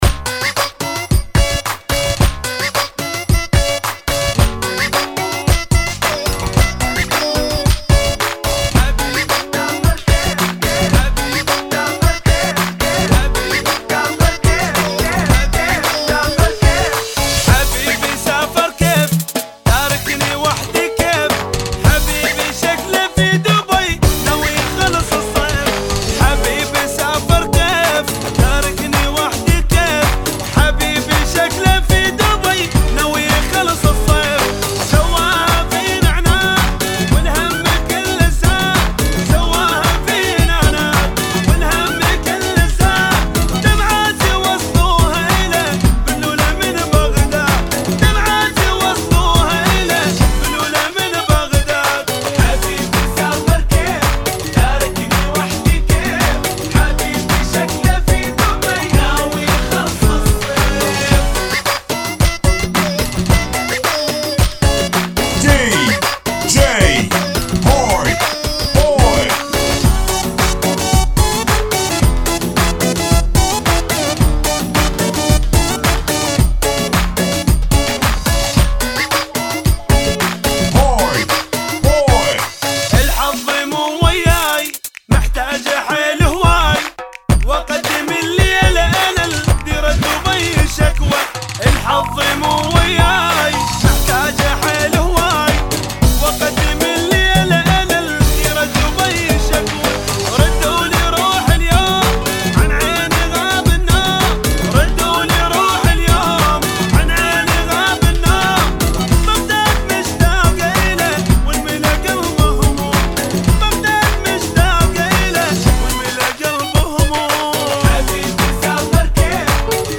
new funky [ 110 Bpm ]